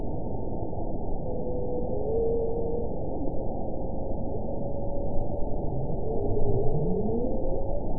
event 922419 date 12/31/24 time 21:40:00 GMT (11 months ago) score 8.84 location TSS-AB06 detected by nrw target species NRW annotations +NRW Spectrogram: Frequency (kHz) vs. Time (s) audio not available .wav